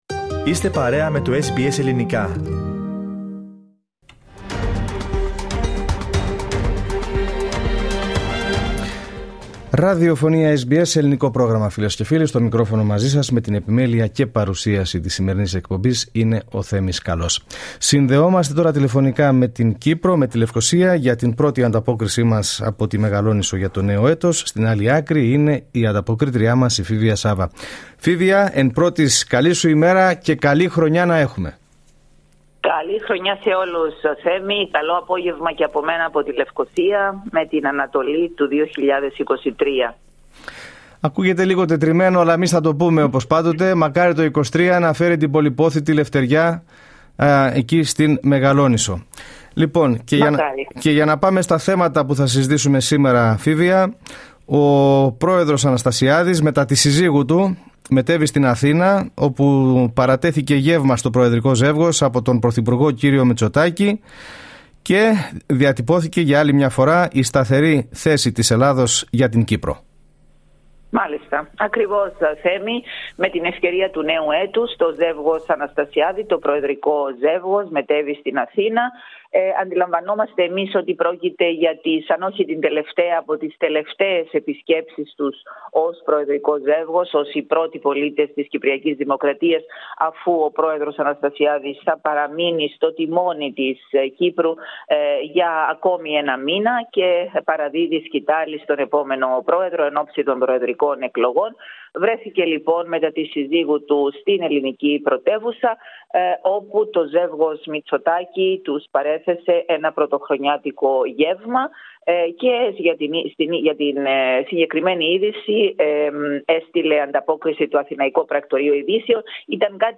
Για να ακούσετε ολόκληρη την εβδομαδιαία ανταπόκισή μας από την Κύπρο, πατήστε το Play κάτω από τον τίτλο της είδησης.